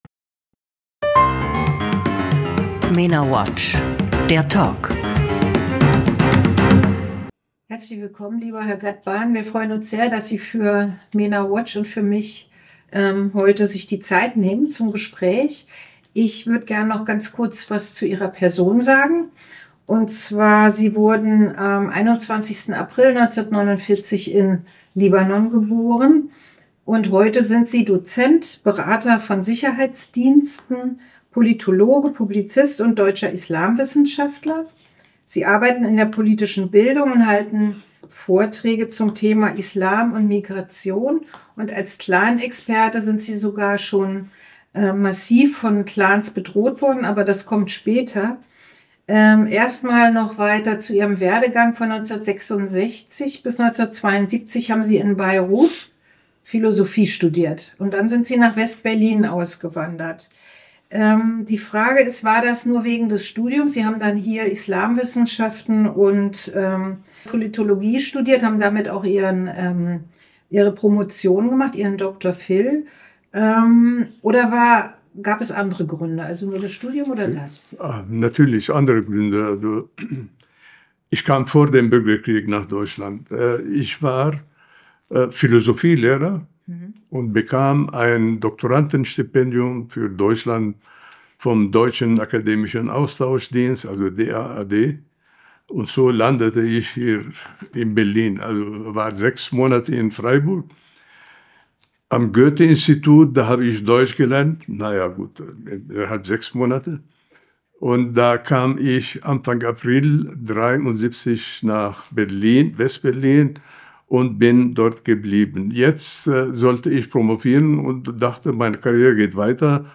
Ein Gespräch in drei Teilen, dessen erste Folge vor zwei Wochen hier und vor einer Woche hier erschien.